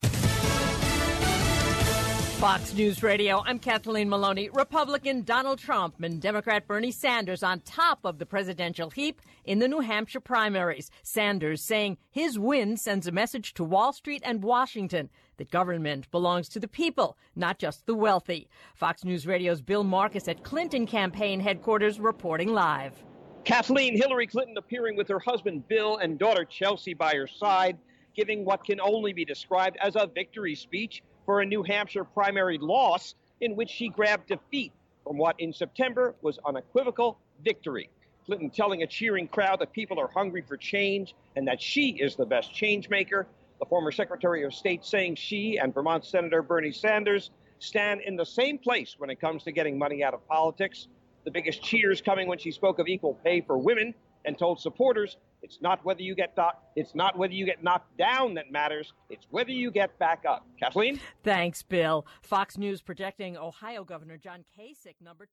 (MANCHESTER, NH) FEB 9 – FOX NEWS RADIO, LIVE, 10PM
FOX-NEWS-10PM-LIVE.mp3